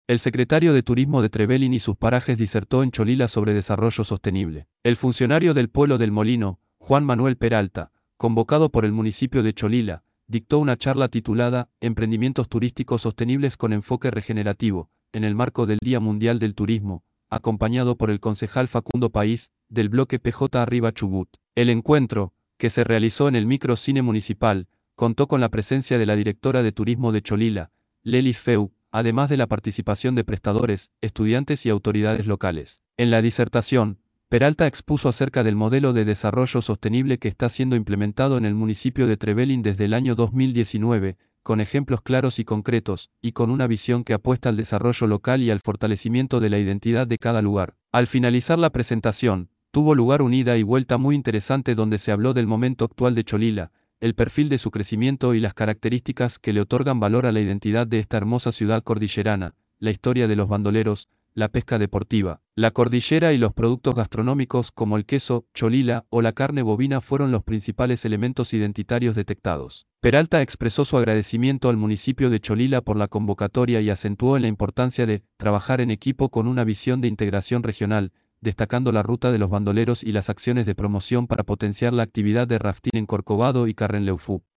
disertacion_sec._turismo_en_cholila.mp3